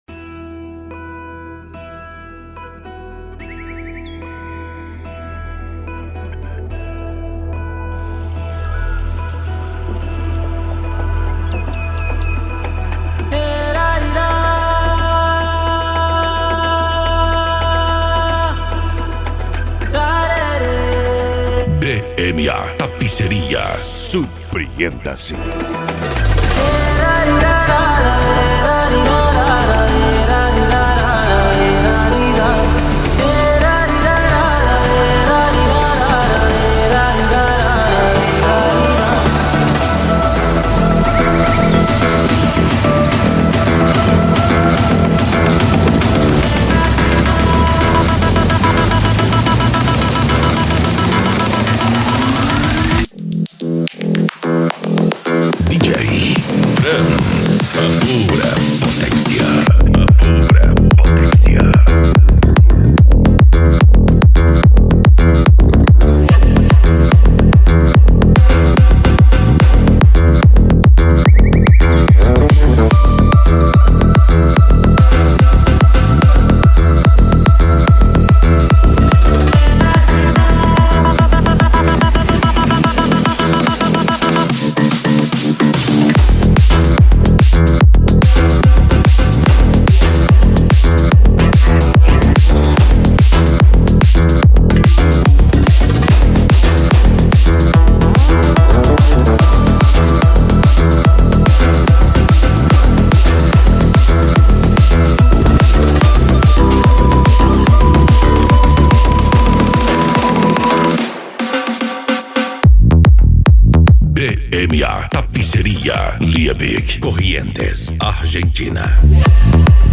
Bass
Deep House
Electro House
Eletronica
Techno Music
Trance Music